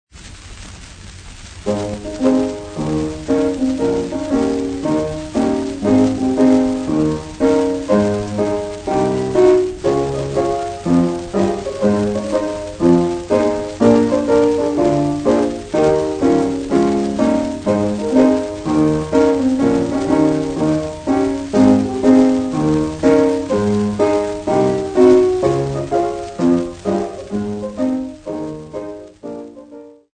Popular music--Africa
Dance music
Dance music--Caribbean Area
sound recording-musical
Euro-African ballad, accompanied by piano